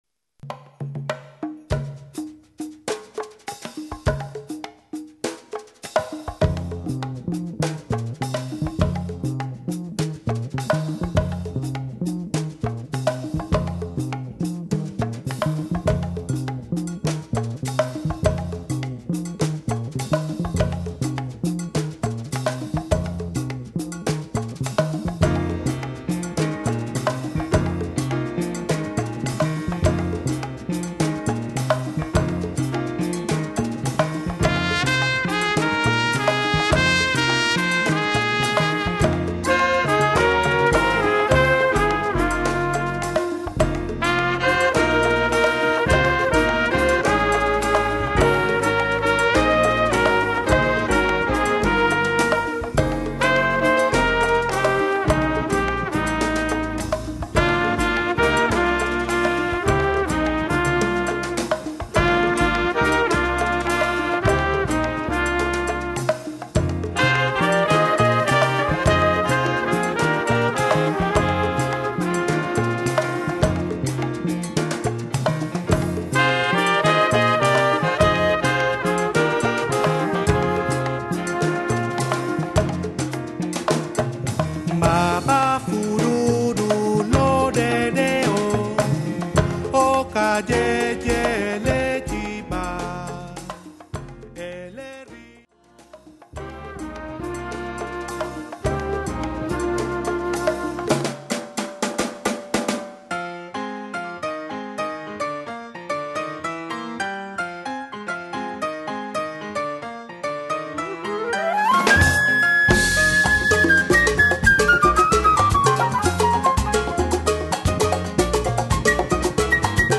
Category: combo (septet)
Style: bata
Solos: *vocal: coro only / no pregón (vocal ad lib)